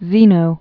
(zēnō, sĭtē-əm) 335?-263?